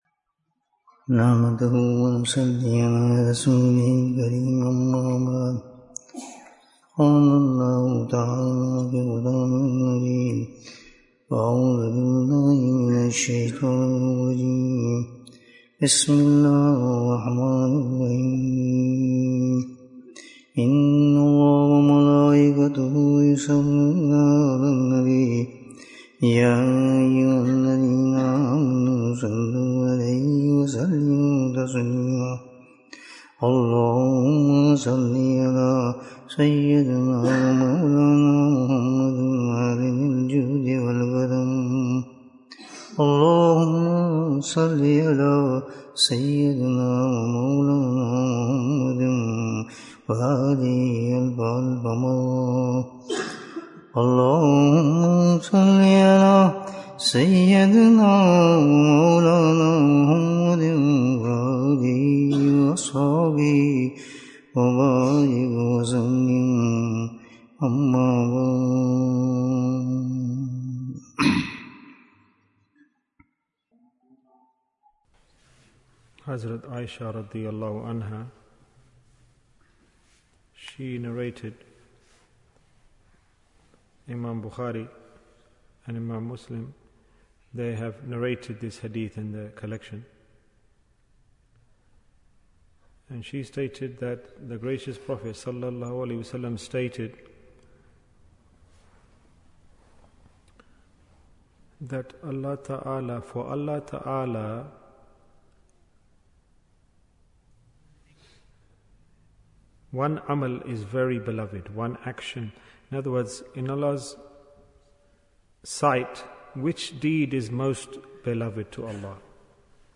The Importance of Punctuality Bayan, 28 minutes1st June, 2023